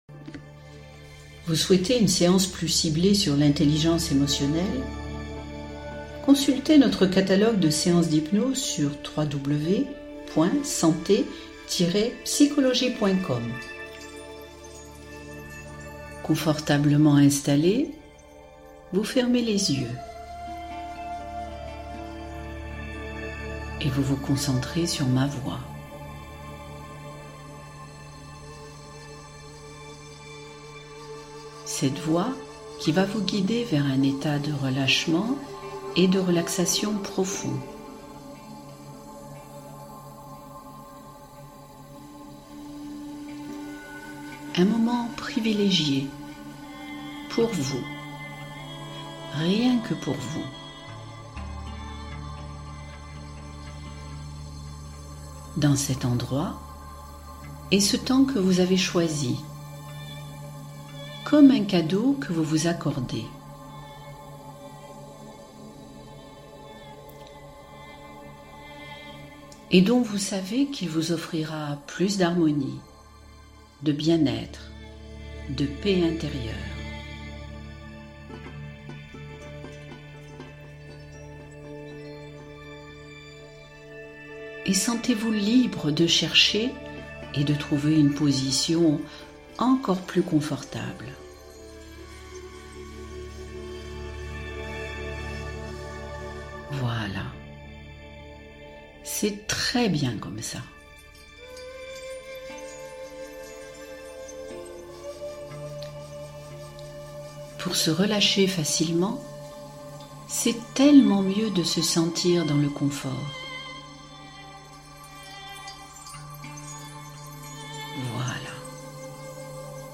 Sommeil et Harmonie : Méditation douce pour une nuit de récupération